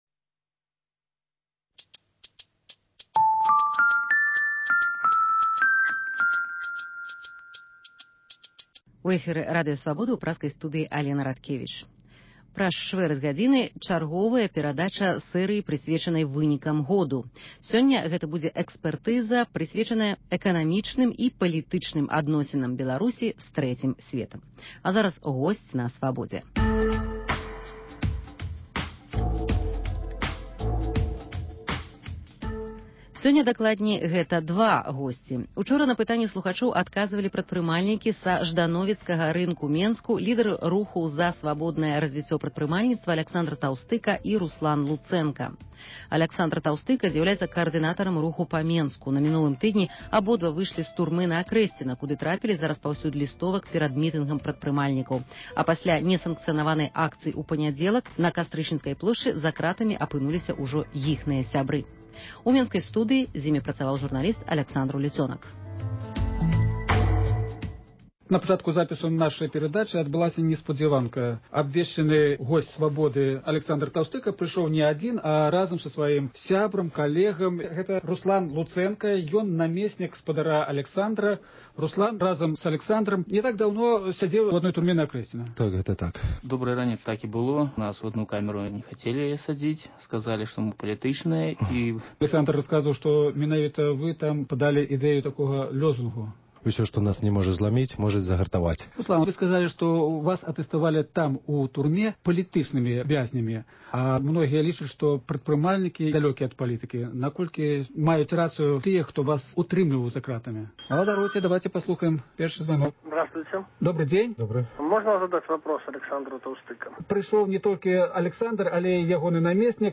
Госьць у студыі адказвае на лісты, званкі, СМСпаведамленьні.